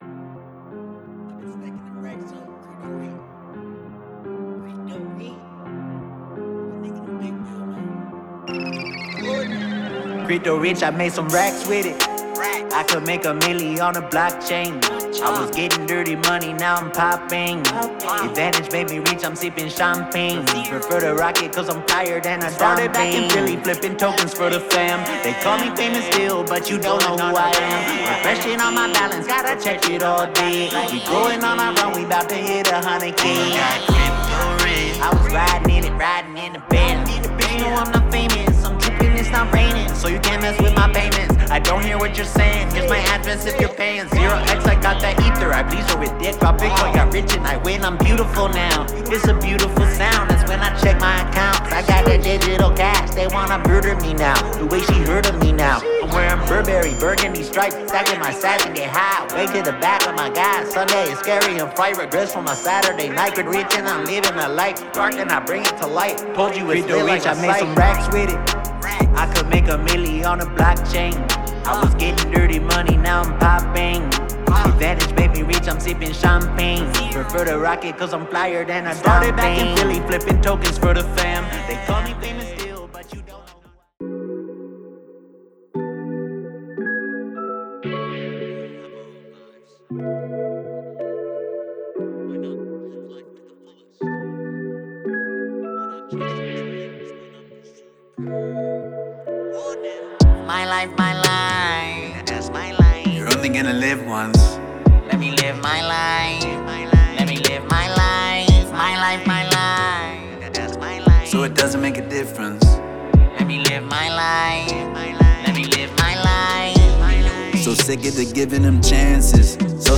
Genre Hip-Hop
Genre Rap